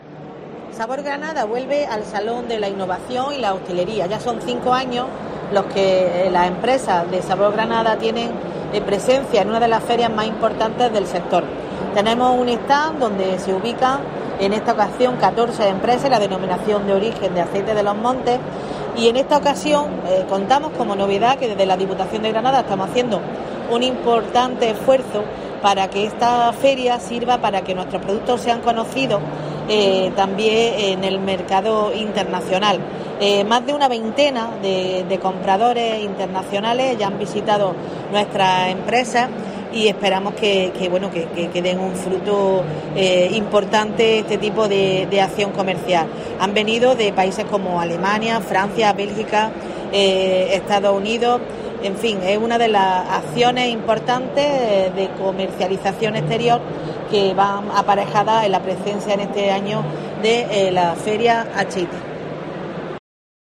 La diputada de Empleo y Desarrollo Sostenible, Ana Muñoz